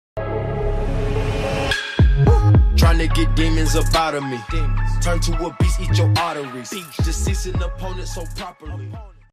twitch-sound-6-follow-sound-alert-sound-and-donation-sound-for-twitch-sound-effect-twitch.mp3